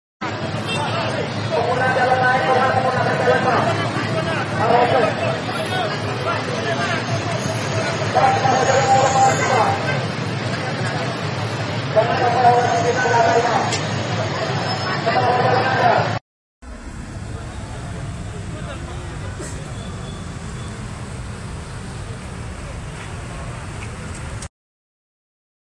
Massa berkumpul sejak Jumat (29/8/2025), kemudian melakukan konvoi menuju Mapolda Riau.